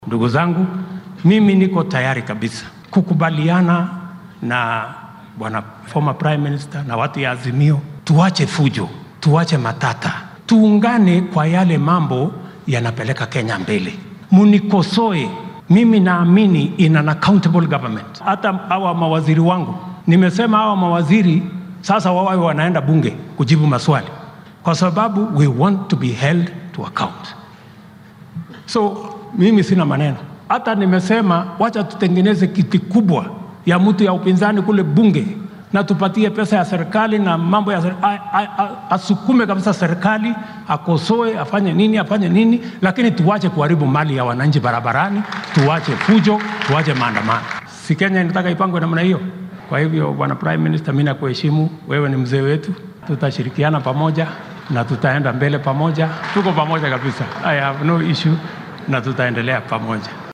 Madaxweynaha dalka William Ruto ayaa siyaasiyiinta isbeheysiga mucaaradka ee Azimio La Umoja-One Kenya ee uu hoggaamiyo Raila Odinga ugu baaqay in xal u raadinta caqabadaha ka taagan wadanka aynan u marin qaab rabshadeysan. Waxaa uu hoosta ka xarriiqay in aan loo baahnayn dibadbaxyo lagu burburinaya hantida shacabka. Ruto ayaa xilli uu shalay ka qayb galay aaska xaaskii halyeeygii ka mid ahaa dadkii u soo halgamay madax bannaanida dalka Dedan Kimathi sheegay inuu diyaar u yahay oo uunan dhibaato ku qabin in maamulkiisa lala xisaabtamo.